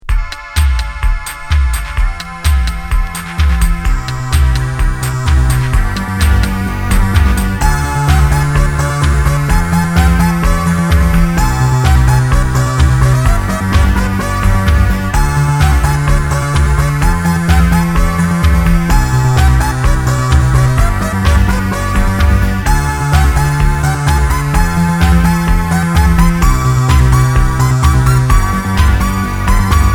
New wave synthétique Unique 45t retour à l'accueil